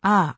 今回は Polly を用いて音声を生成して、出来上がった音声を歌声へ変換にチャレンジします。
あー.wav »
「あー」という発話ができていることがわかります。